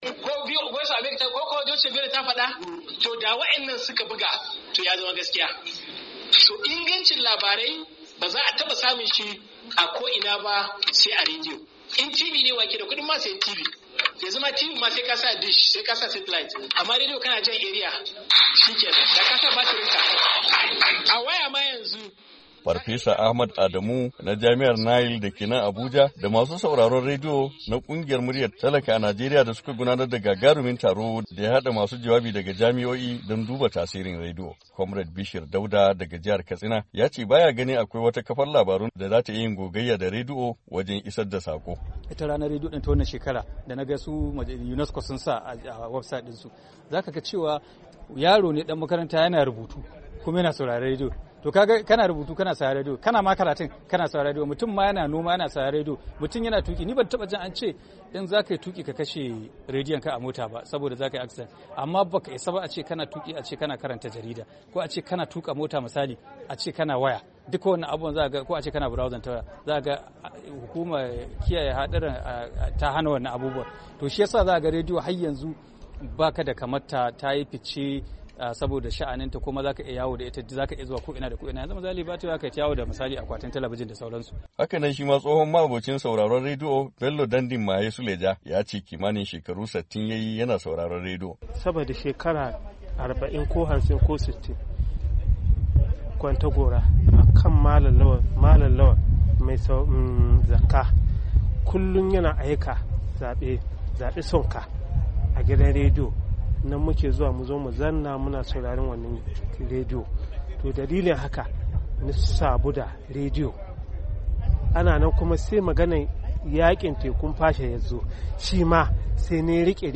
Bikin ranar rediyo ta duniya a Abuja